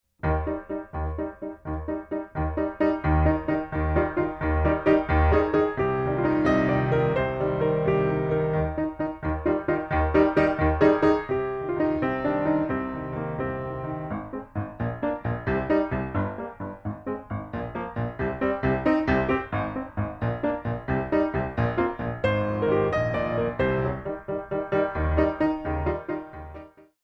Original Music for Ballet Class
Recorded on a Steinway B at Soundscape
4 Count introduction included for all selections
6/8 - 64 with repeat